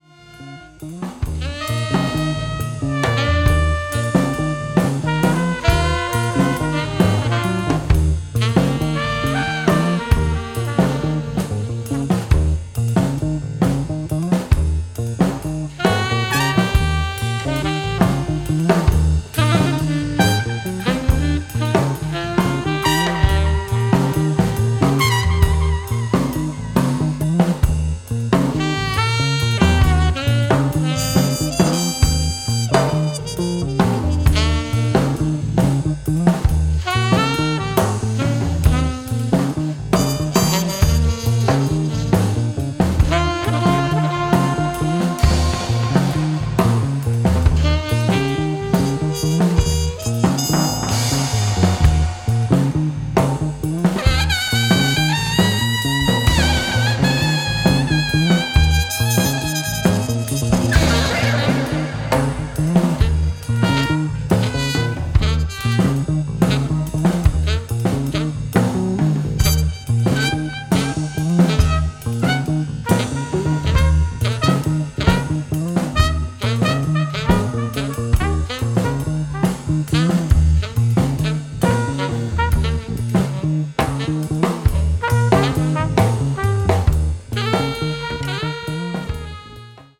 全編通して温かく豊かな味わいに満ちた仕上がりとなっています。